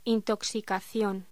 Locución: Intoxicación